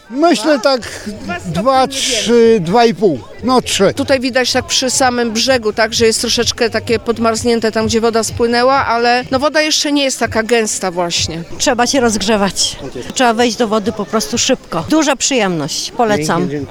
Jaką temperaturę ma teraz?